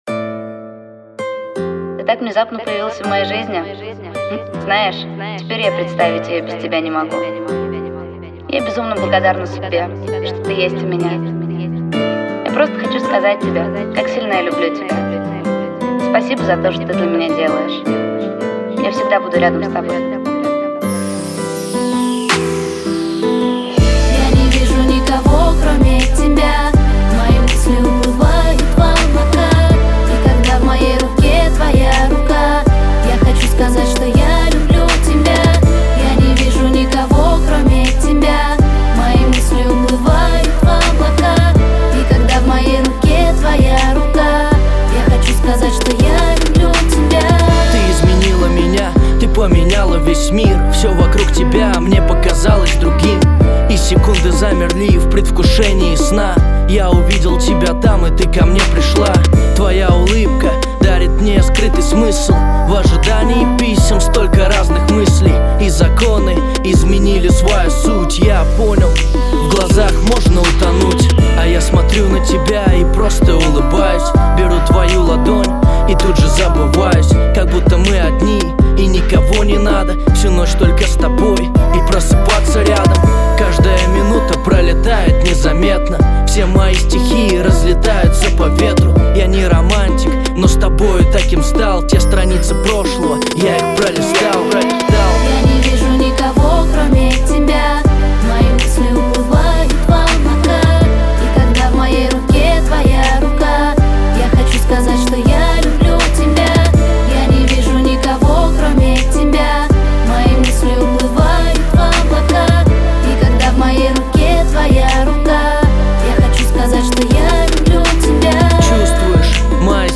??_Ya_lyublyu_tebya_bolshe_zhizni??-rep_o_lyubvi